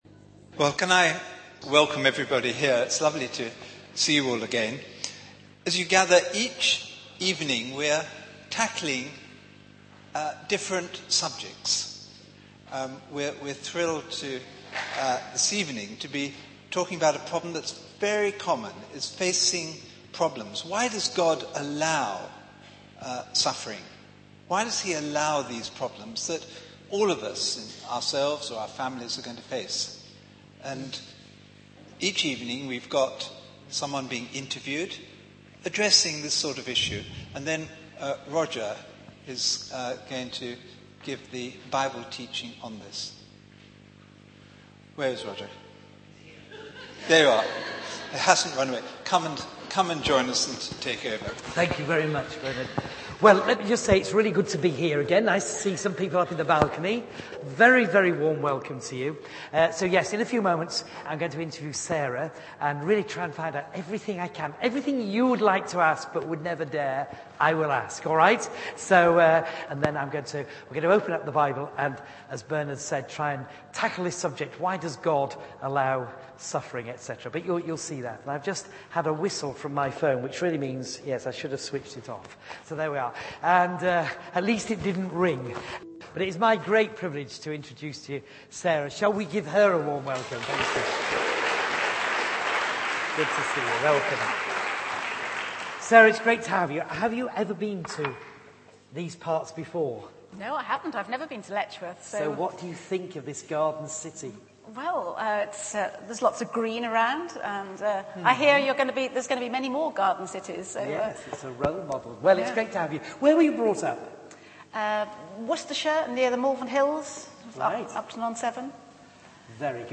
Interview and Testimony